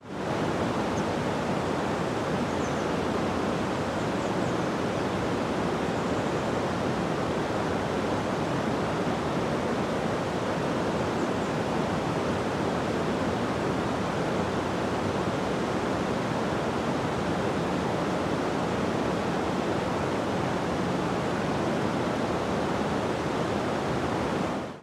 …y dŵr yn byrlymu dros y gored
Water-over-the-weir-at-Horseshoe-Falls.mp3